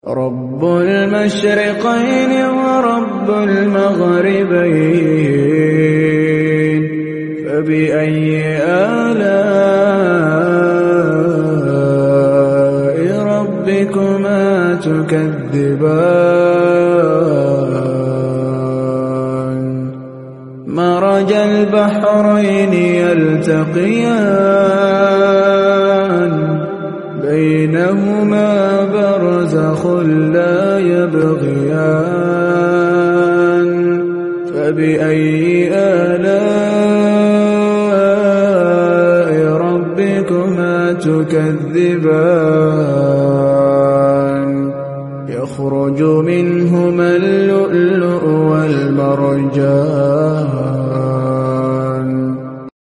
🥀 Recitation Of Holy Quran Sound Effects Free Download